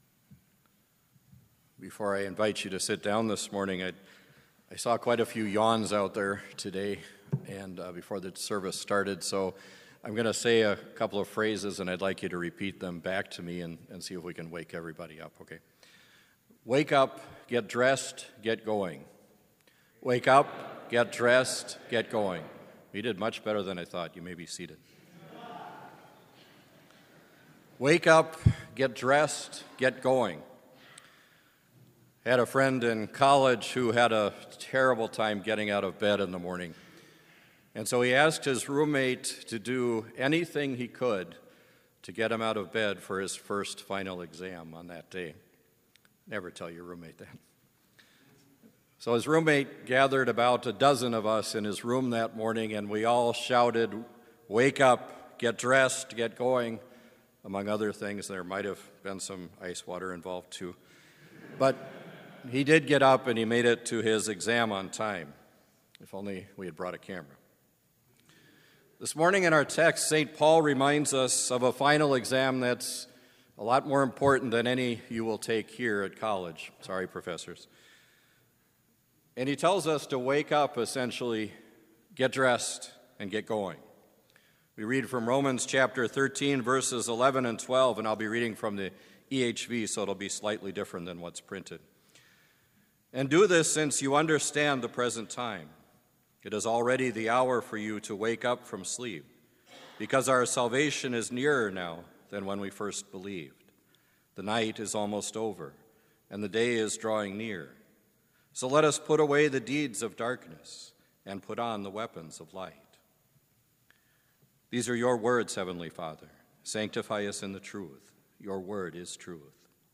Complete Service
• Prelude
• Hymn 538 - The Day is Surely Drawing Near View
• Devotion
• Prayer
• Postlude